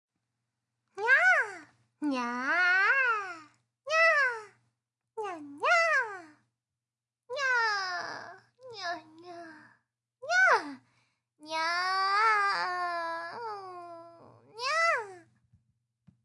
Download Free Anime Girl Sound Effects
Anime Girl